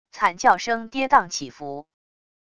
惨叫声跌宕起伏wav音频